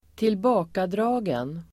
Uttal: [²tilb'a:kadra:gen]